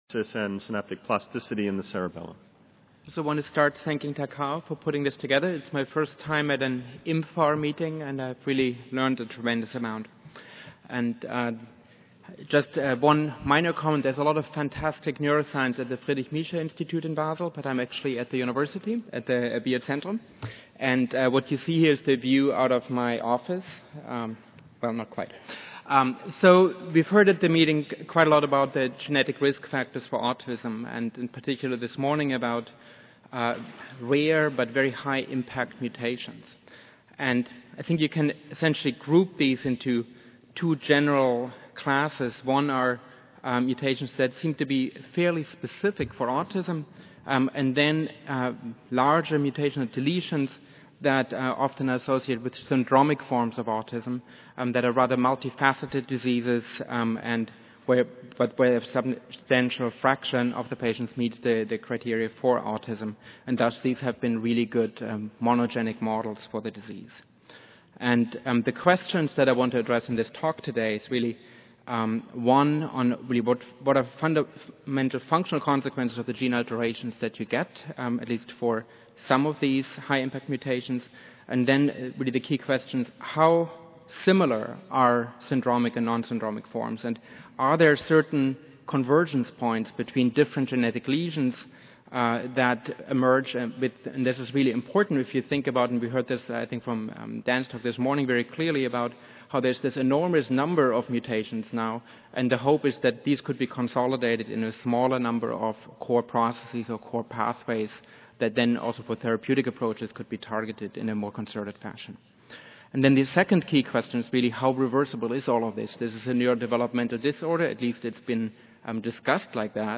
Auditorium (Kursaal Centre)
Recorded Presentation